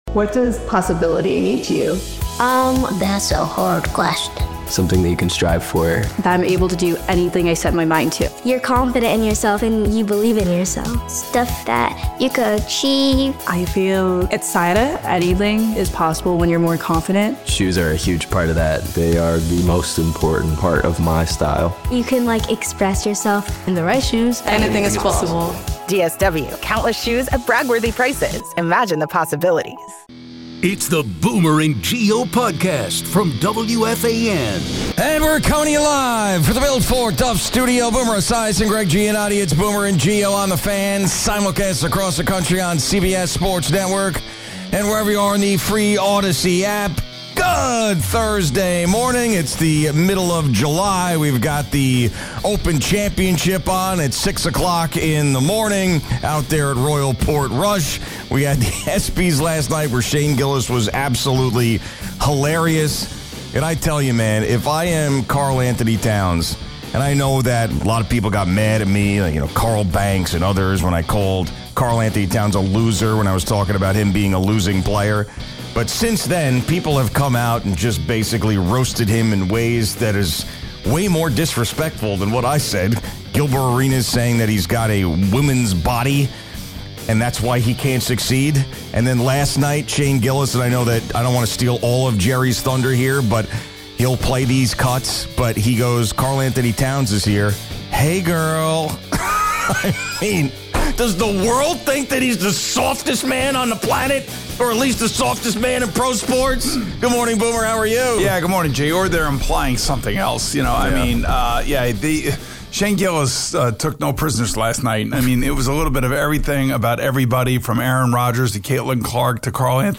And we're coming you live for the billed for Duff Studio.